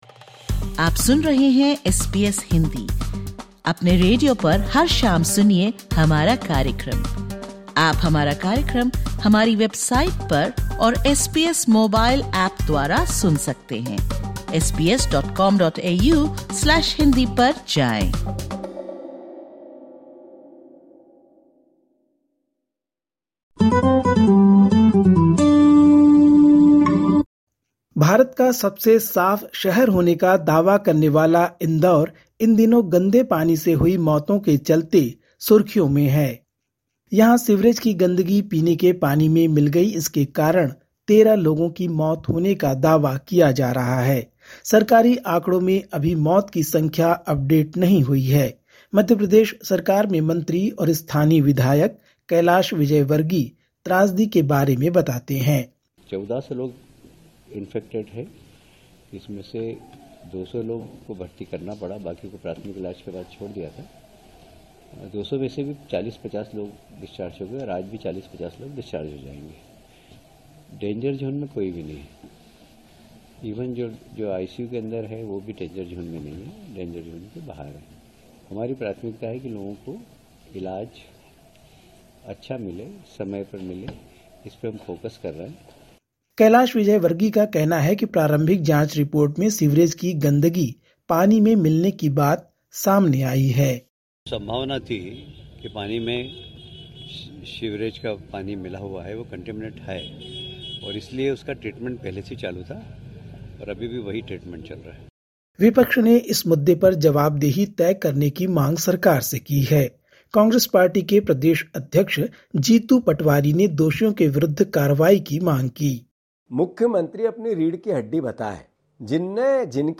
Listen to the latest SBS Hindi news from India. 02/01/2026